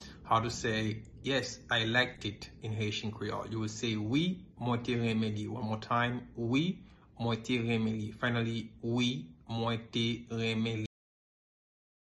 Pronunciation:
Yes-I-liked-it-in-Haitian-Creole-Wi-mwen-te-renmen-li-pronunciation-by-a-Haitian-Creole-teacher.mp3